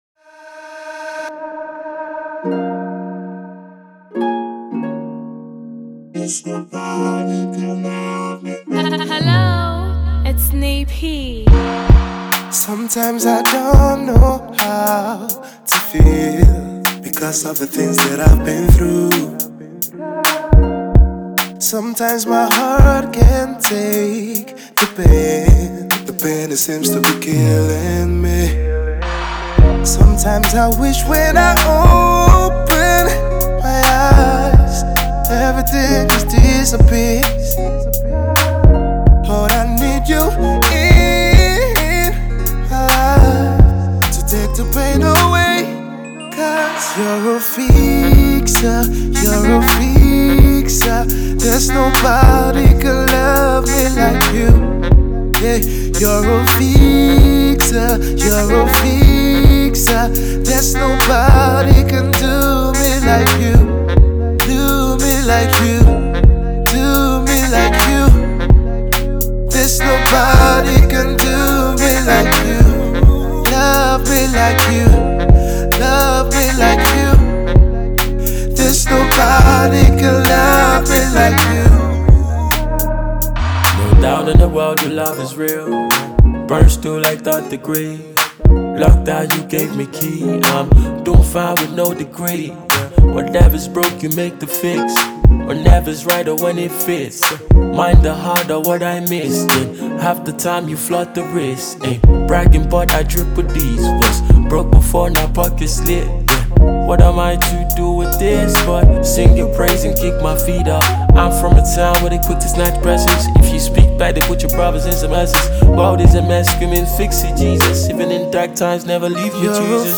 features a fine blend of RnB with Afro-pop elements.